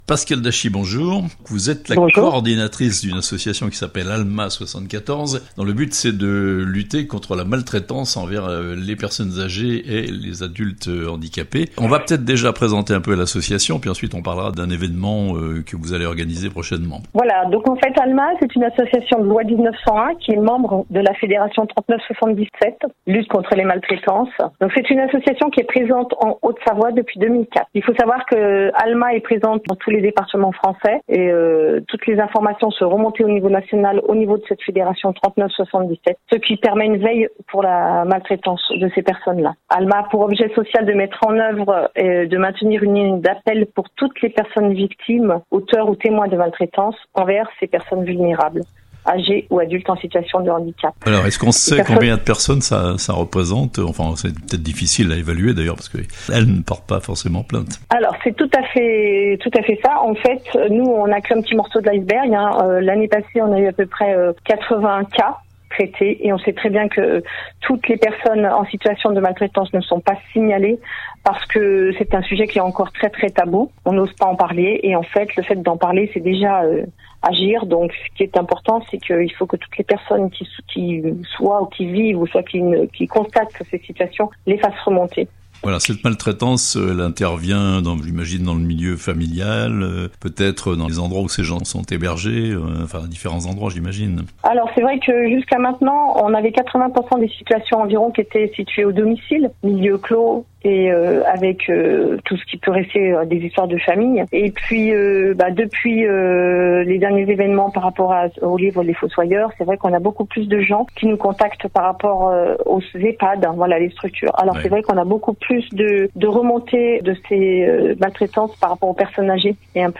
Maltraitance des personnes âges et des personnes handicapées, un théâtre-débat pour en parler (interview)